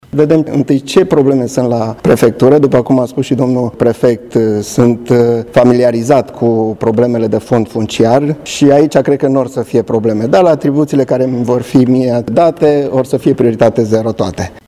La festivitatea de depunere a jurământului de către noul subprefect, a fost prezent şi comisarul şef de poliţie Dan Mihai Chirică, secretarul de stat pentru relaţia cu instituţiile prefectului, în Ministerul Afacerilor Interne.
La prima sa declaraţie, subprefectul Adrian Laurenţiu Folea a spus: